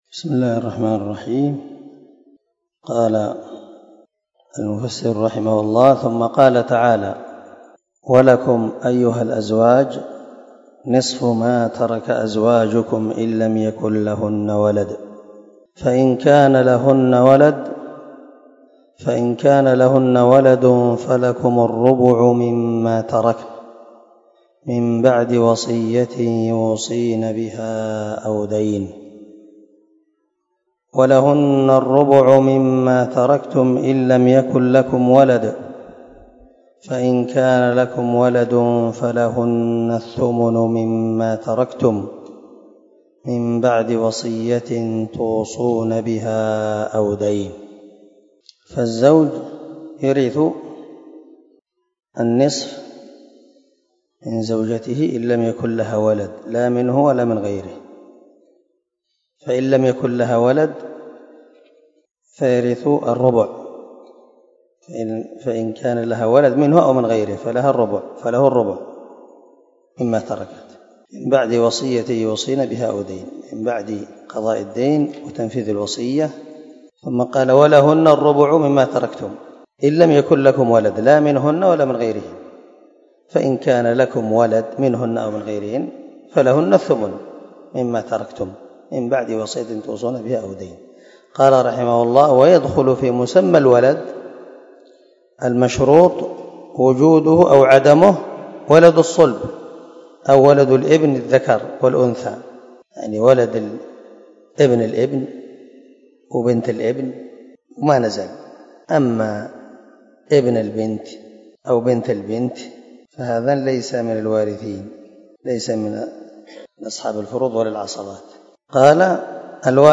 244الدرس 12 تابع تفسير آية ( 11 - 12 ) من سورة النساء من تفسير القران الكريم مع قراءة لتفسير السعدي
دار الحديث- المَحاوِلة-